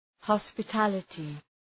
Προφορά
{,hɒspə’tælətı}